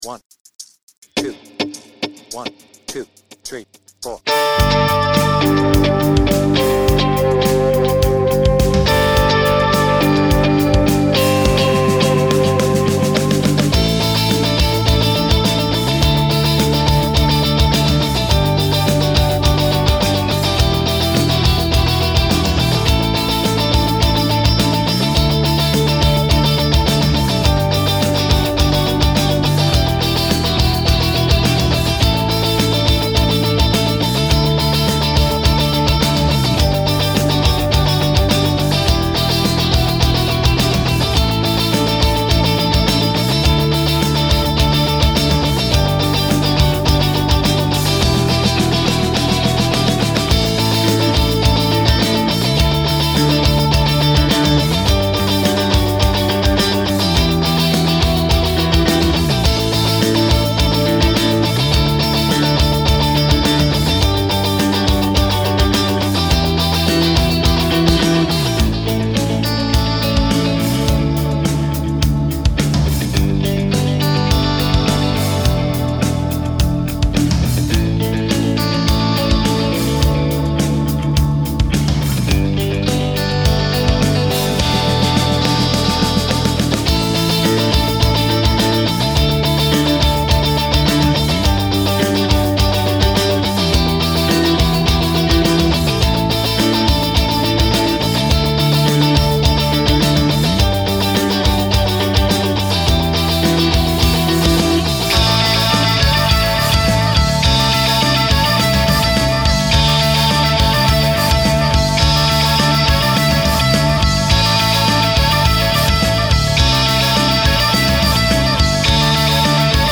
BPM : 102
Tuning : Eb
Without Vocals